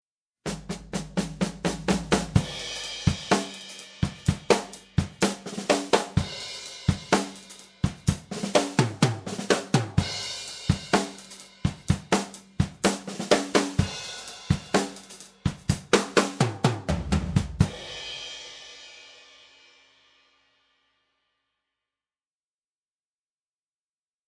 Here’s a short drum passage that I’ll use to illustrate this technique in action:
The dry signal comes from 5 mics: Kick, snare, floor tom, and 2 overheads.
tipIIComp-Dry.mp3